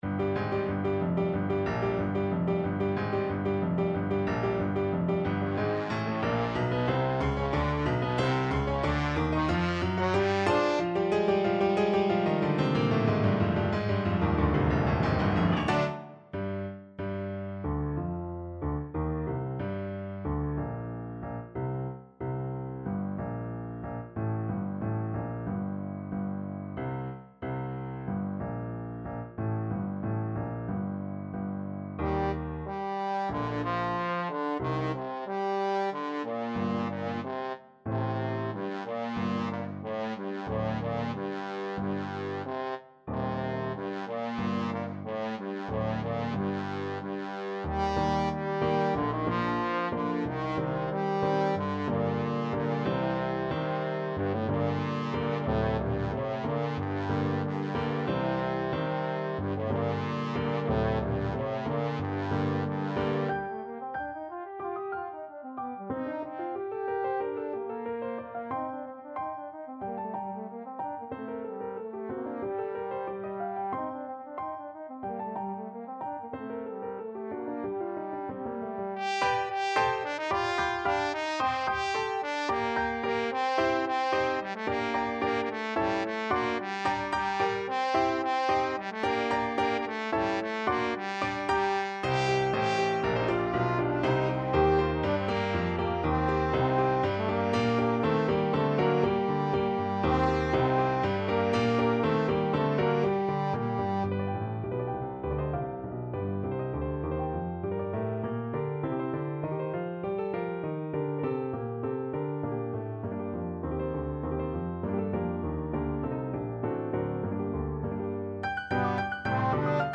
Allegro =92 (View more music marked Allegro)
2/4 (View more 2/4 Music)
Classical (View more Classical Trombone Music)